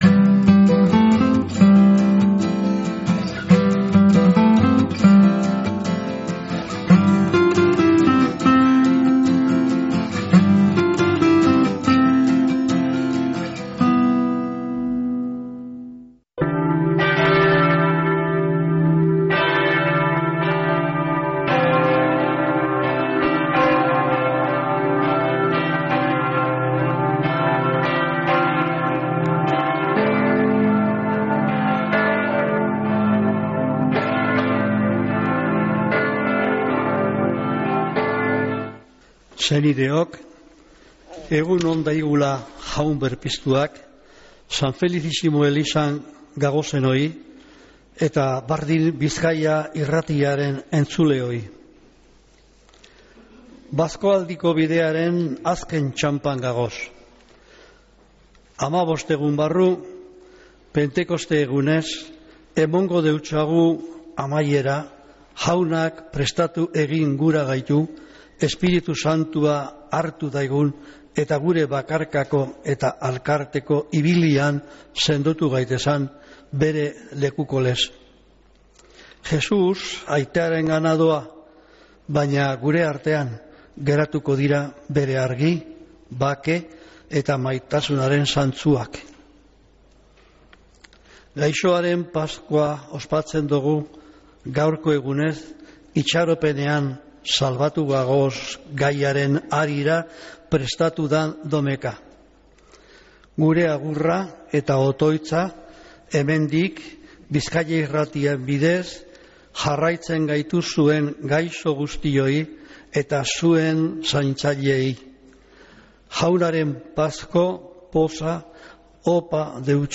Mezea San Felicísimo parrokiatik | Bizkaia Irratia